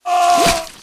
音效多为锚和鱼的声音。